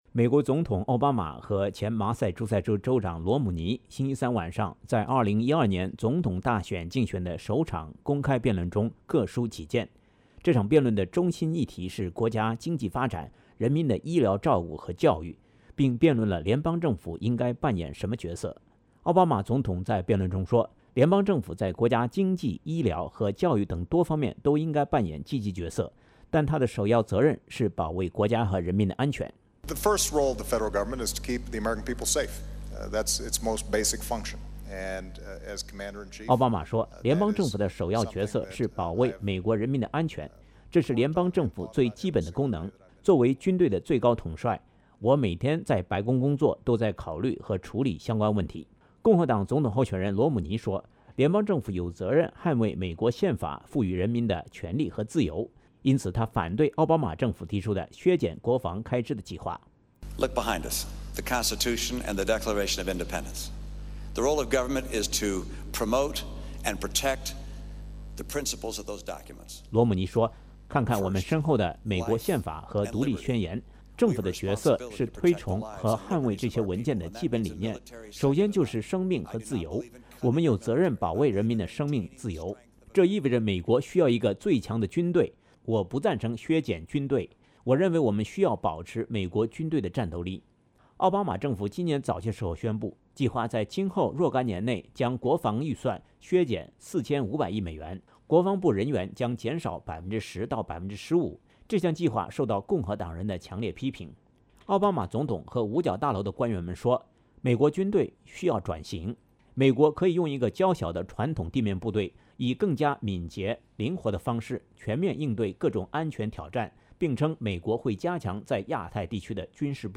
美国总统奥巴马和前麻萨诸塞州州长罗姆尼在丹佛大学举行了2012年总统大选的首场公开辩论。这场辩论的中心议题是经济发展，医疗照顾和教育，并辩论了联邦政府的角色。美国著名电视主播吉姆.莱勒主持这场辩论。